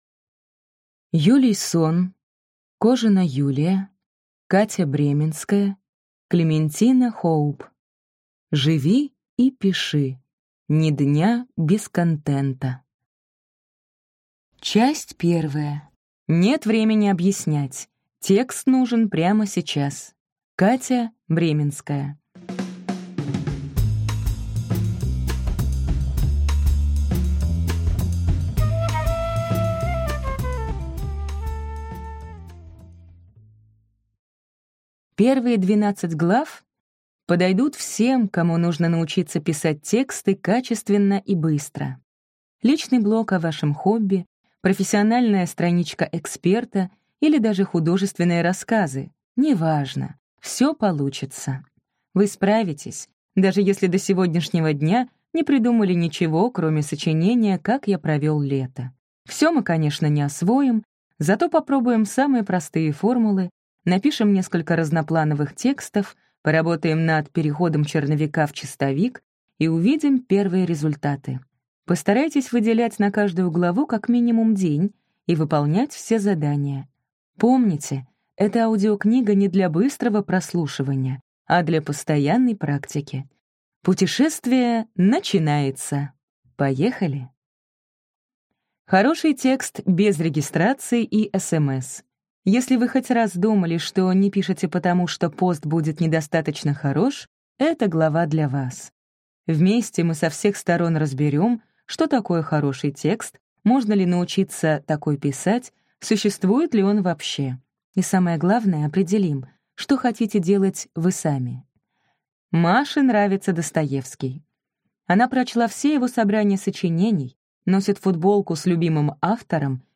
Аудиокнига Живи и пиши: ни дня без контента | Библиотека аудиокниг